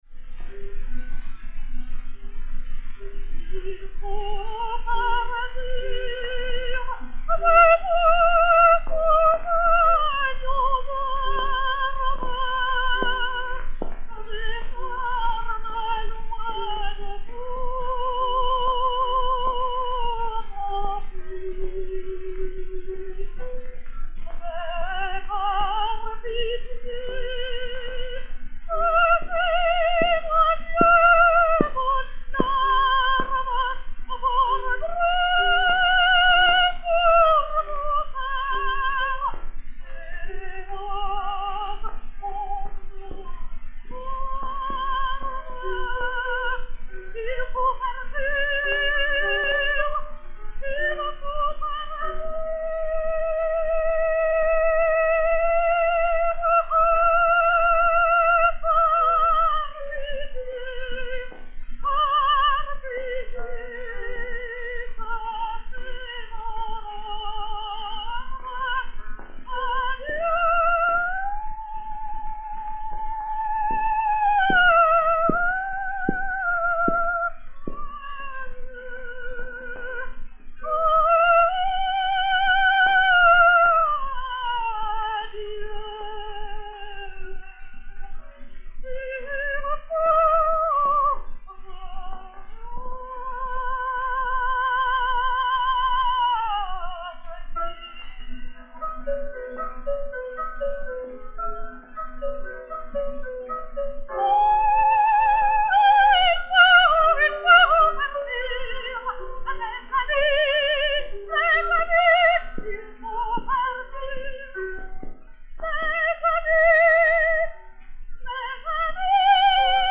Jane Mérey (Mimi) et Piano